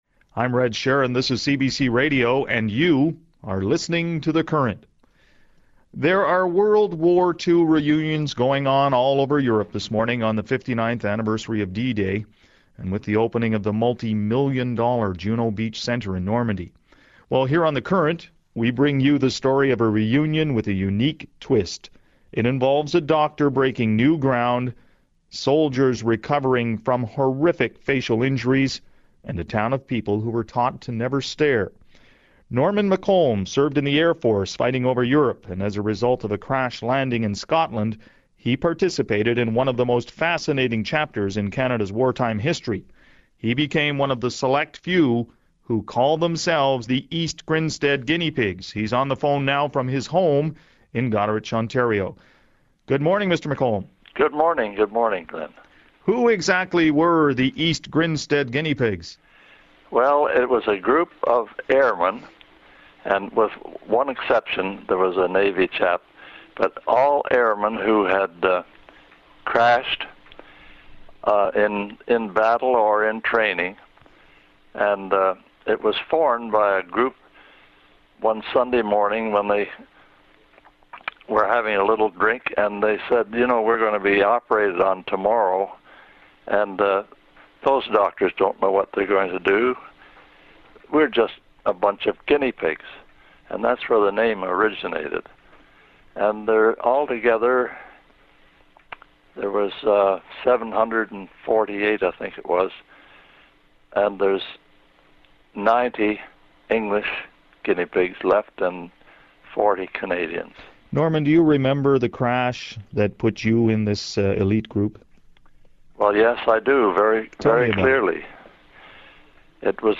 CBC Radio Interview